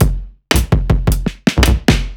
Index of /musicradar/off-the-grid-samples/110bpm
OTG_Kit9_Wonk_110b.wav